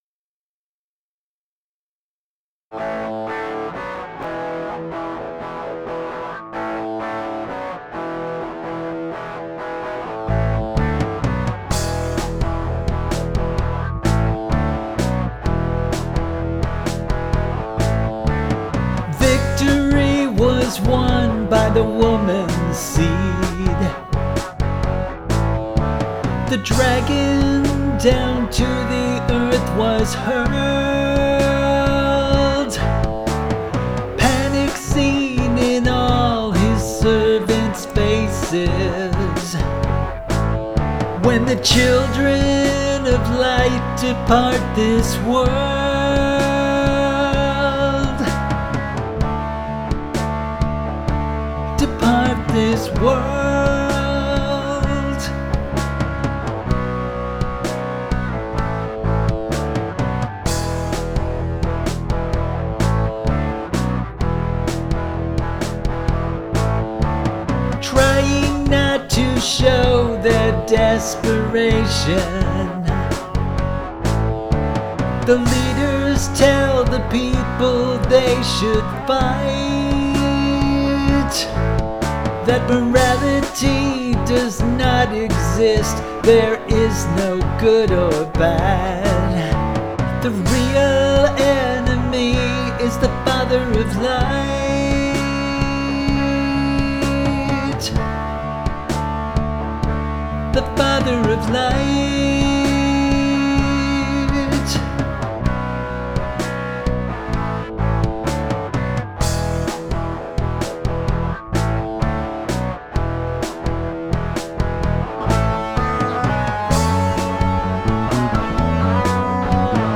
Vocals, Guitars, Bass